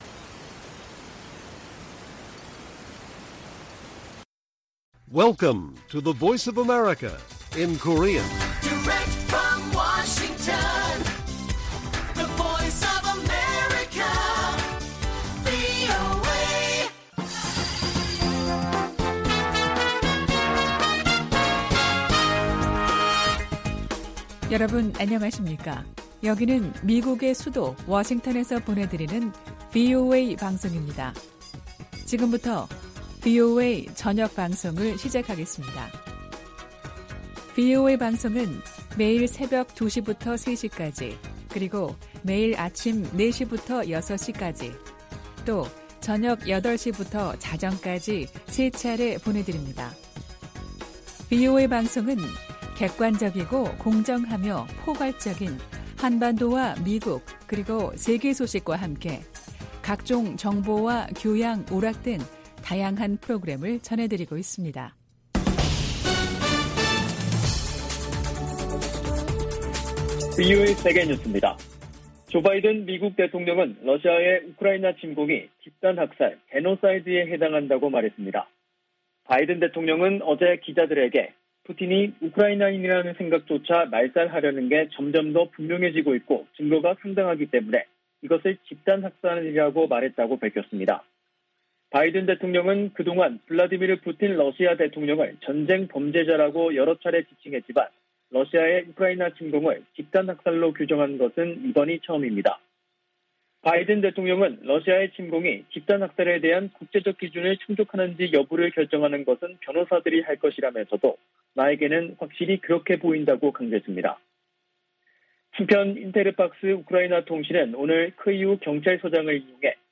VOA 한국어 간판 뉴스 프로그램 '뉴스 투데이', 2022년 4월 13일 1부 방송입니다. 미 국무부는 연례 인권보고서에서 북한이 세계에서 가장 억압적이고 권위주의적인 국가라고 비판했습니다. 미 국방부는 북한이 전파 방해와 같은 반우주역량을 과시하고 있으며, 탄도미사일로 인공위성을 겨냥할 수도 있다고 평가했습니다. 한국 윤석열 차기 정부의 초대 외교부와 통일부 장관에 실세 정치인들이 기용됐습니다.